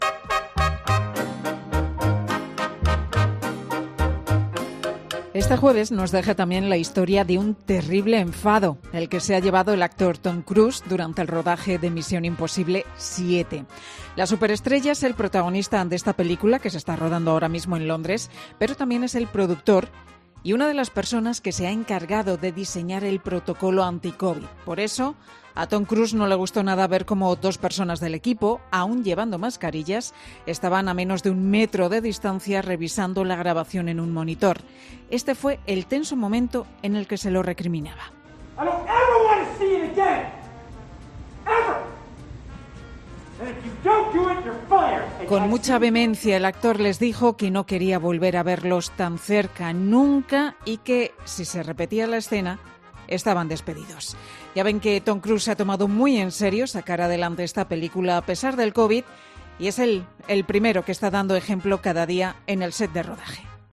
Pues, como ven, con mucha vehemencia el actor les dijo a estos dos trabajadores que no quería volver a verlos tan cerca nunca.